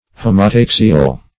Search Result for " homotaxial" : The Collaborative International Dictionary of English v.0.48: Homotaxial \Ho`mo*tax"i*al\, Homotaxic \Ho`mo*tax"ic\, a. (Biol.)
homotaxial.mp3